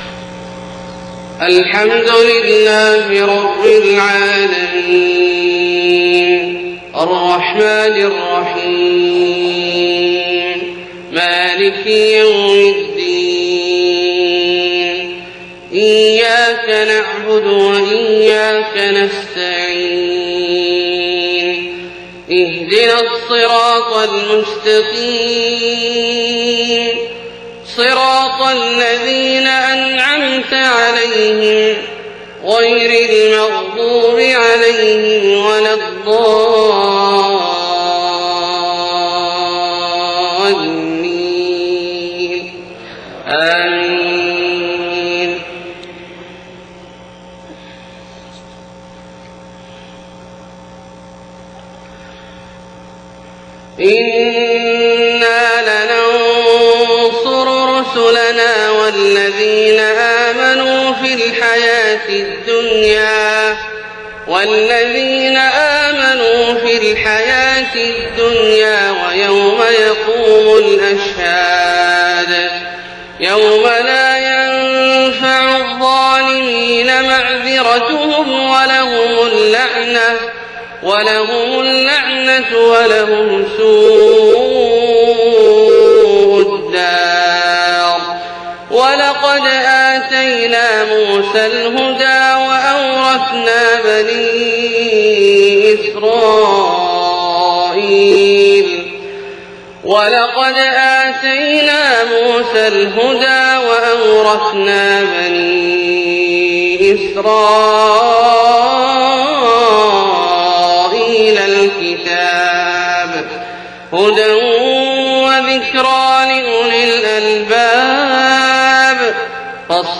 صلاة الفجر 18 محرم 1430هـ من سورة غافر 51-66 > 1430 🕋 > الفروض - تلاوات الحرمين